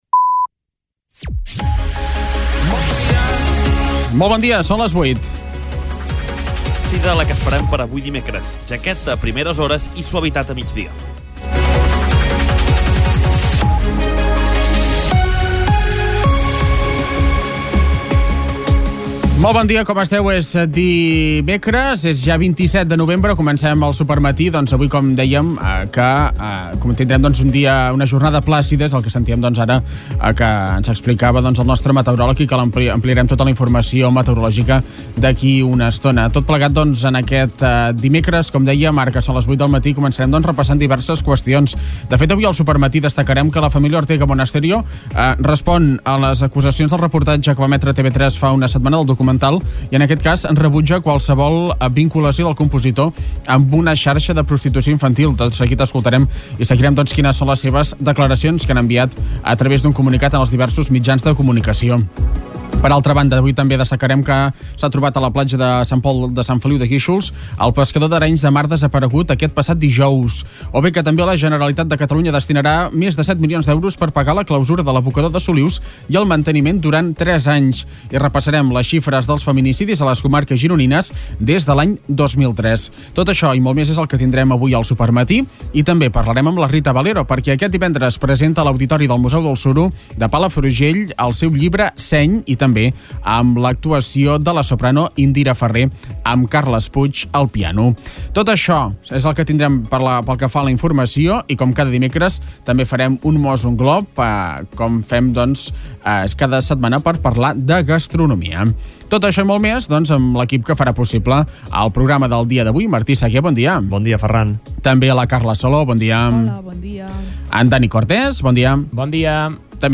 Escolta l'informatiu d'aquest dimecres